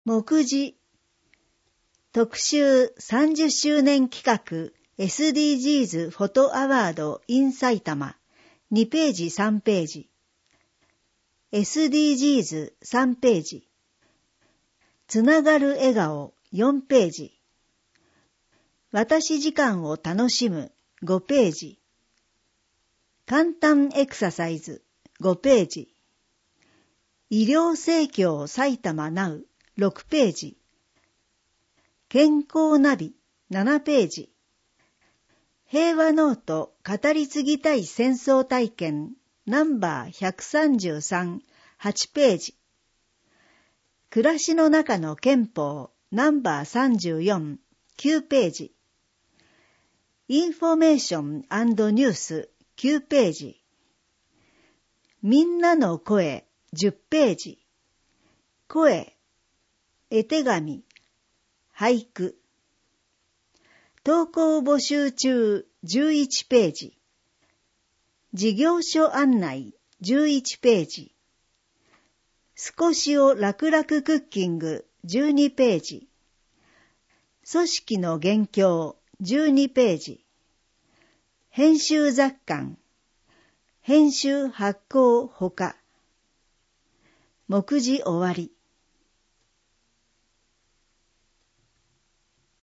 2022年10月号（デイジー録音版）